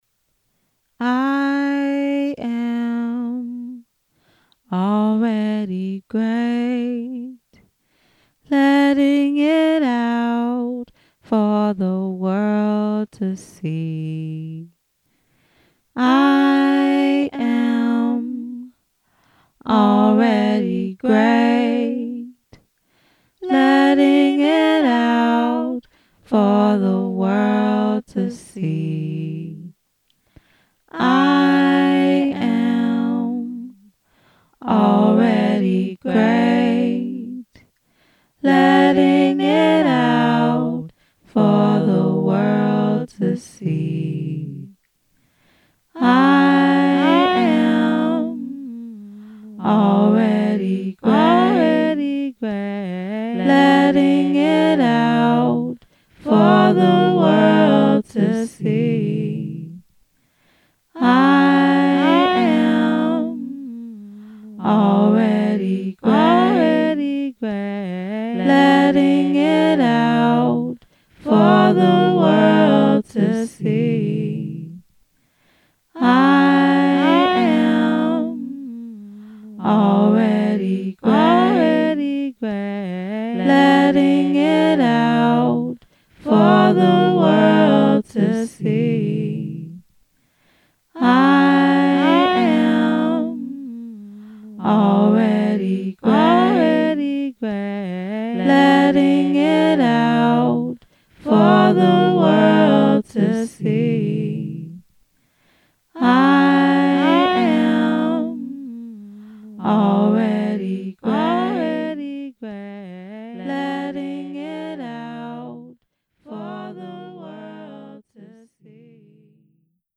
this looks like melodic affirmations.
sings melodic affirmations after work to affirm her power and decompress after the workday